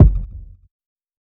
TC2 Kicks20.wav